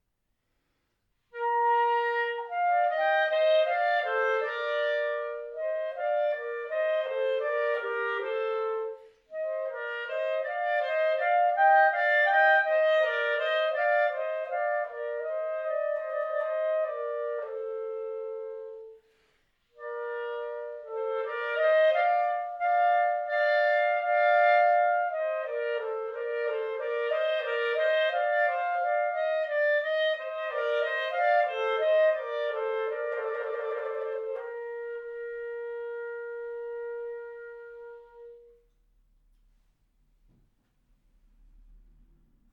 Besetzung: 2 Klarinetten